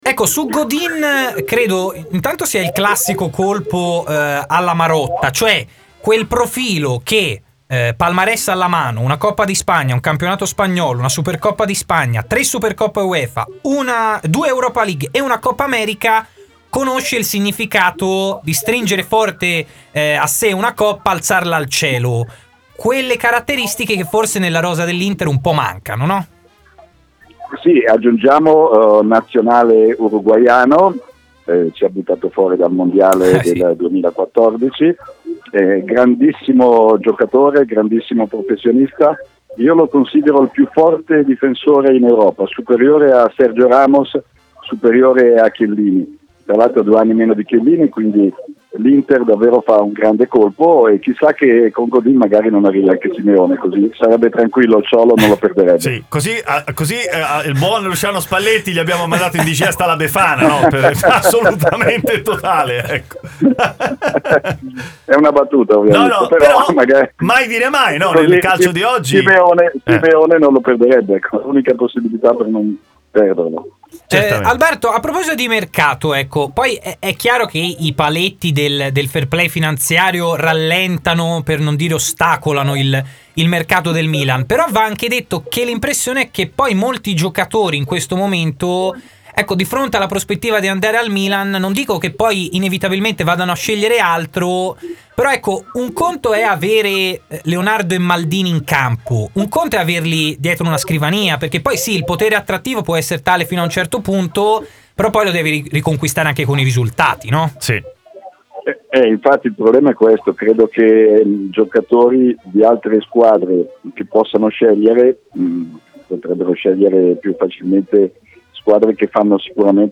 in diretta su RMC Sport, ha commentato i temi più caldi di giornata: